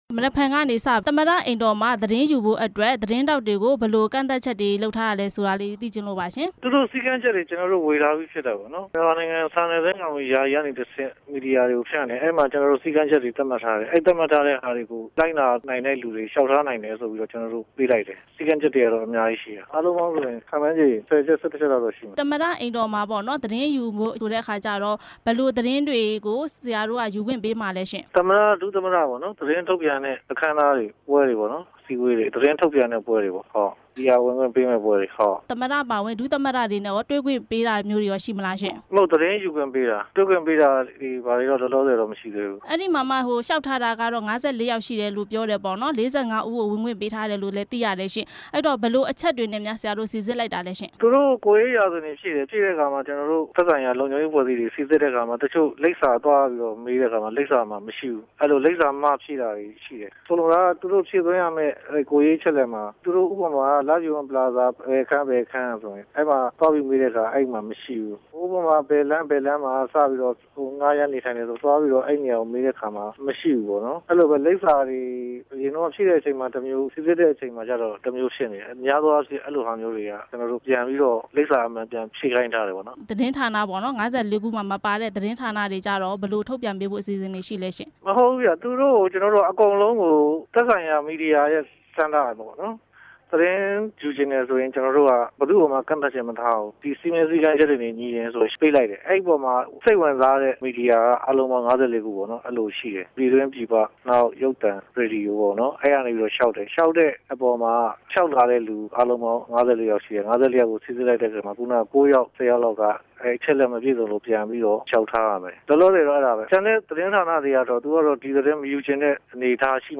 သမ္မတရုံး ညွှန်ကြားရေးမှူး ဦးဇော်ဌေးကို မေးမြန်းချက်